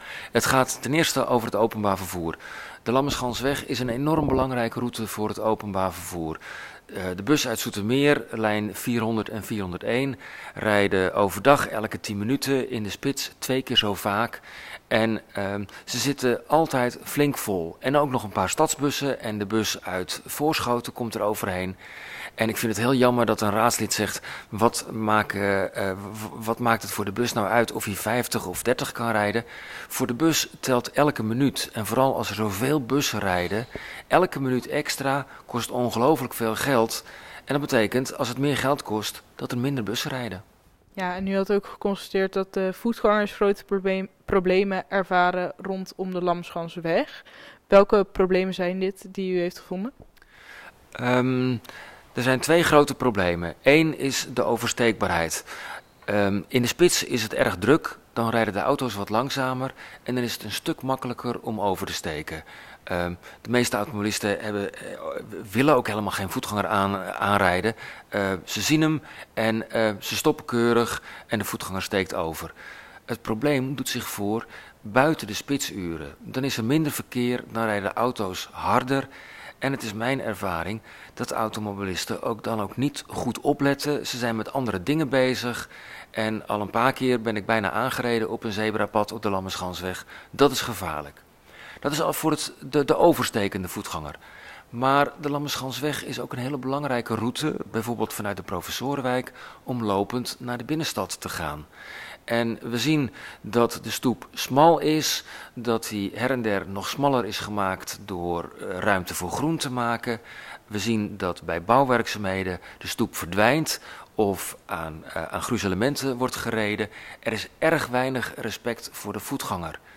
Verslaggever